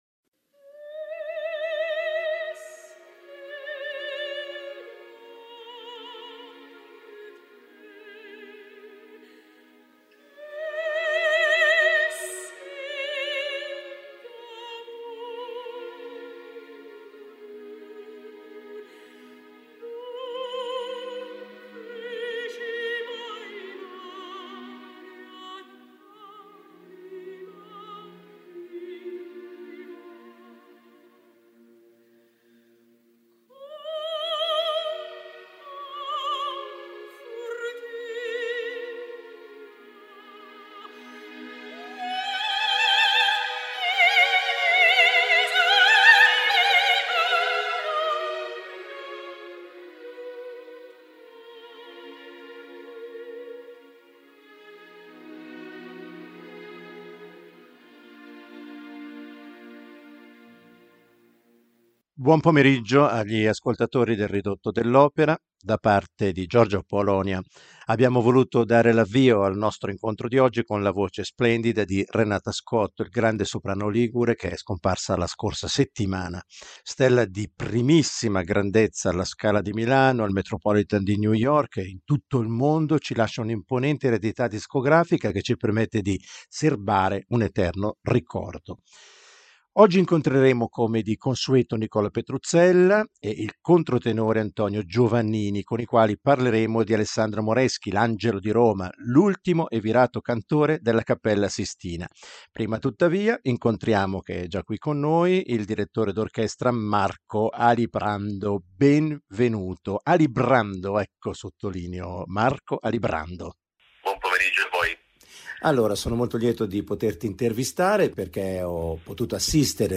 La scorsa settimana abbiamo raccontato la vita di Giovanni Battista Velluti, praticamente l'ultimo castrato a comparire sulla scena, oggi quella di Alessandro Moreschi, il cosiddetto "Angiolo di Roma", ultimo castrato - o quasi - della Cappella Sistina. Di lui rimangono tracce acustiche che non mancheremo di proporre.